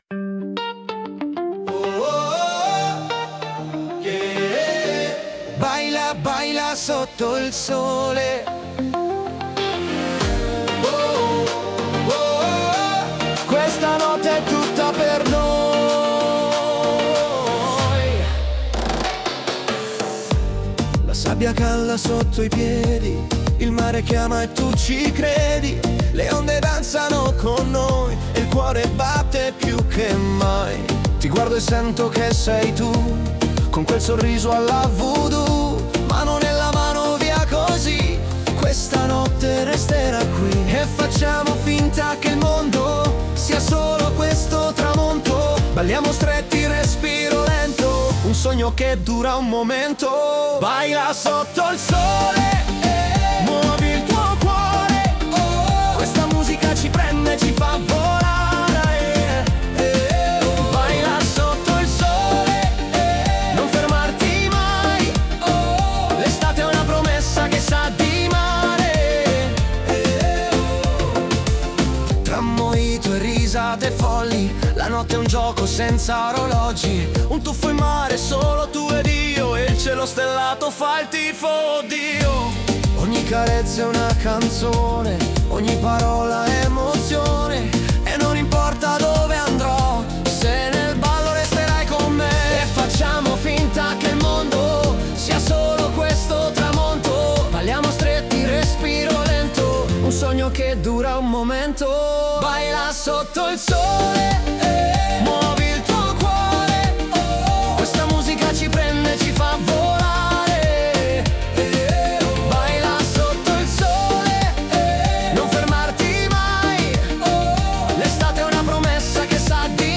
Latino